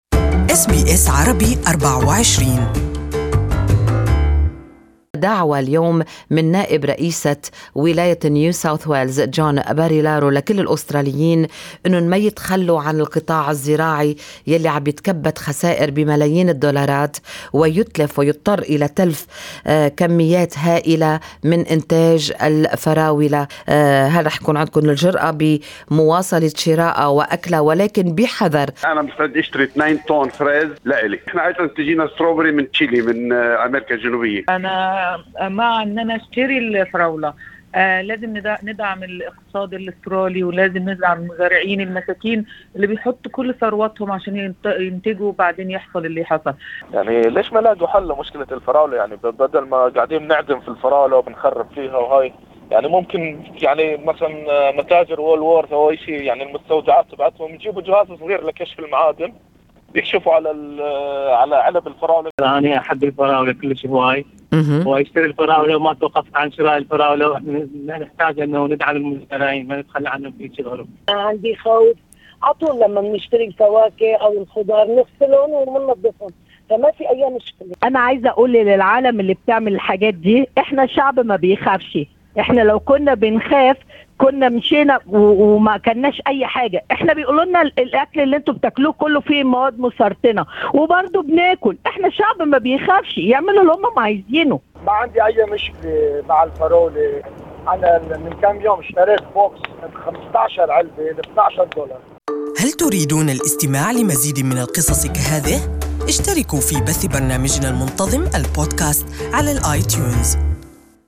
Good Morning Australia dedicated its talk-back segment to ask the audience of what they think of the strawberry contamination saga and to ask whether they'll refrain or not from buying fruits.